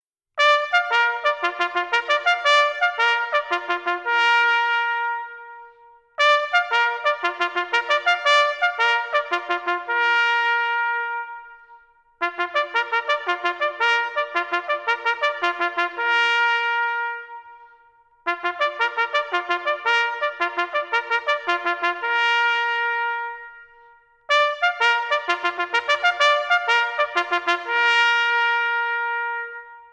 • Soundtrack